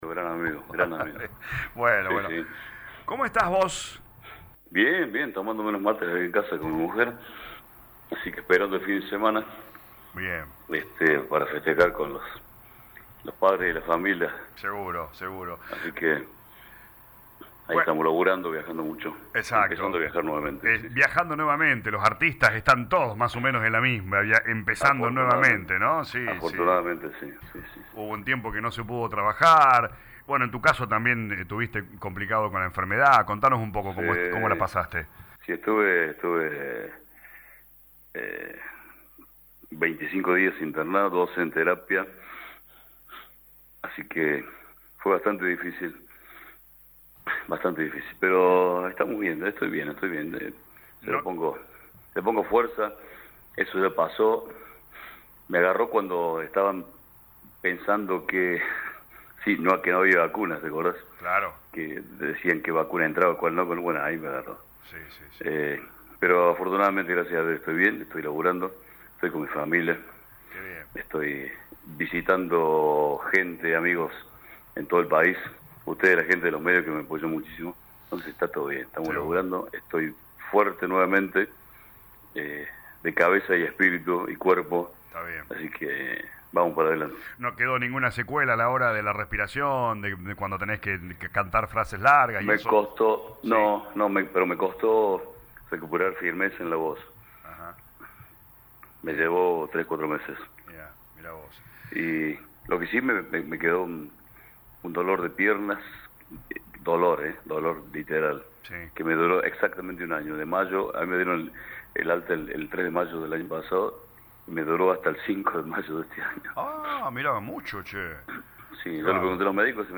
En el marco de un nuevo show que brindará en nuestra ciudad, el «Toro Quevedo» dialogó con LA RADIO 102.9 FM y contó que luego de estos dos años de pandemia está con más ganas que nunca de pisar fuerte en los escenarios del país.